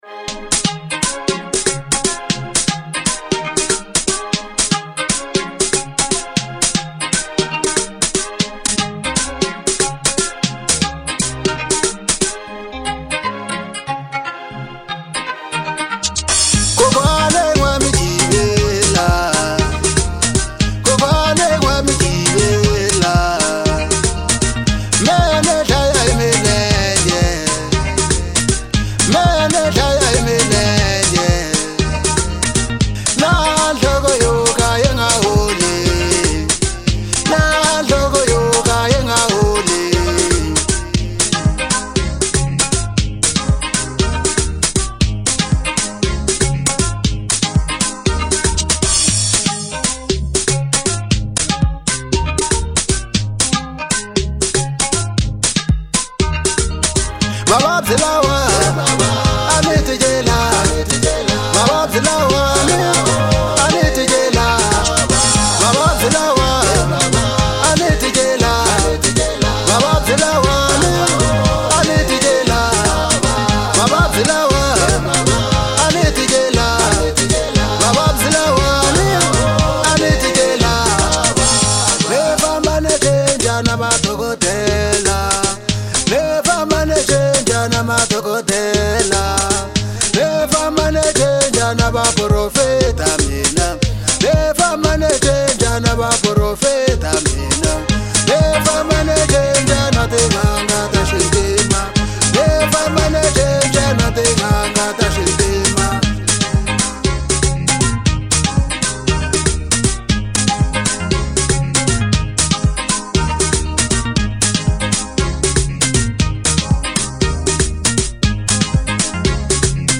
04:16 Genre : African Disco Size